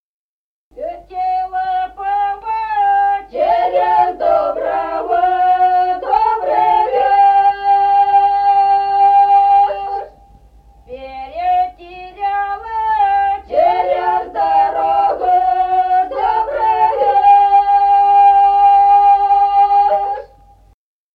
| diskname = Песни села Остроглядово.
| filedescription = Летела пава (щедровка).